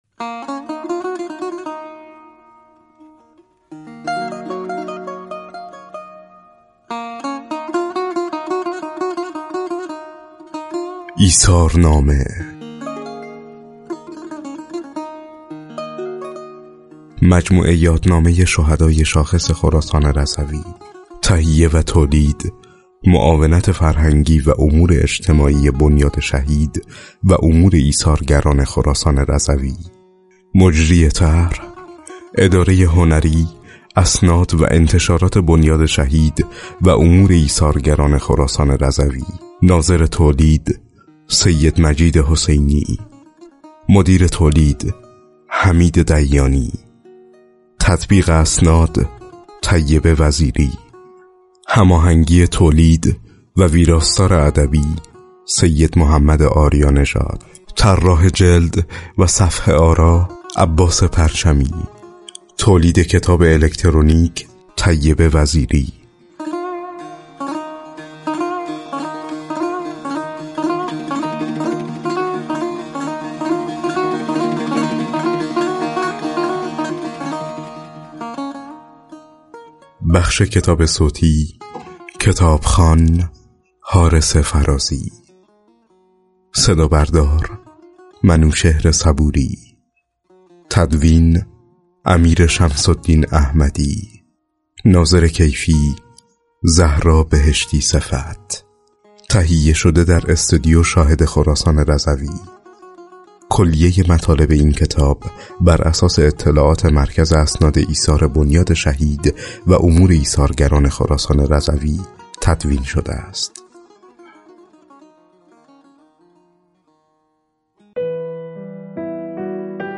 بر این اساس کتاب‌های جیبی، الکترونیکی، و کتاب صوتی 72 تن از شهیدان شاخص استان از میان شهیدان انقلاب اسلامی، ترور، دفاع مقدس، مرزبانی، دیپلمات و مدافع حرم منتشر و رونمایی شده است.